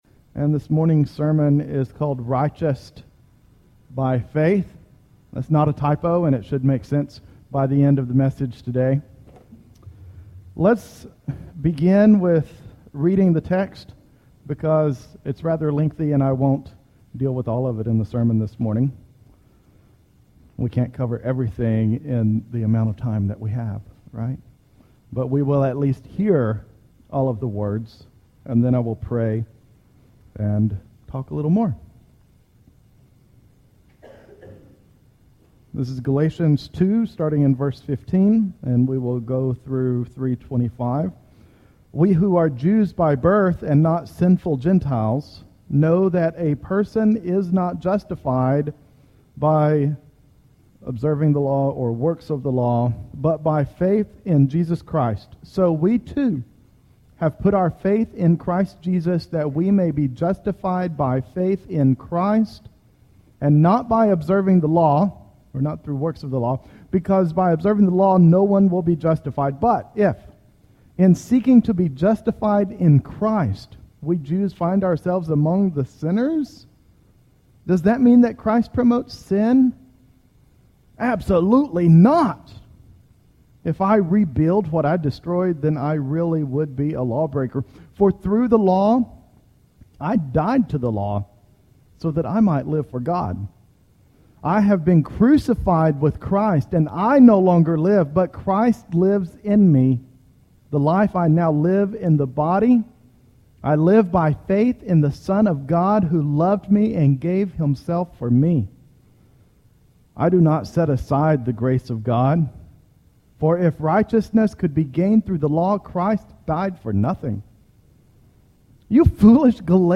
Most Recent Sermons Older Sermons Audio Sermons